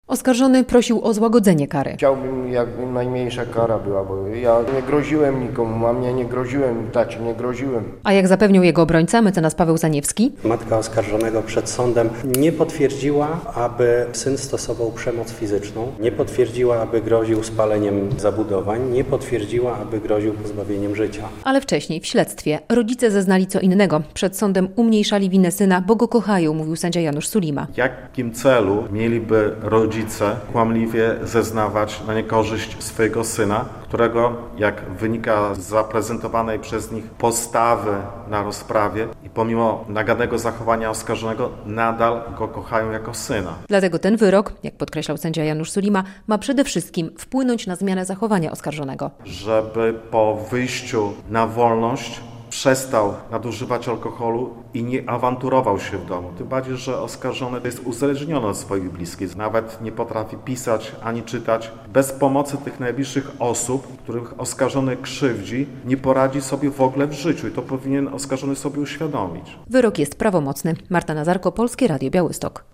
Wyrok za znęcanie się nad rodzicami - relacja